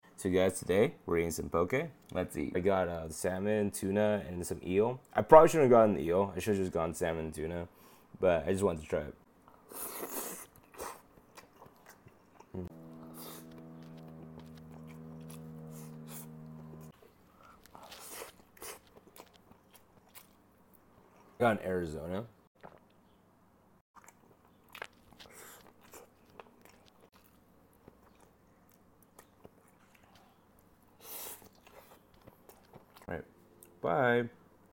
This video was recorded a couple months ago and I don’t really remember why my voice is like that. Maybe because I was sick.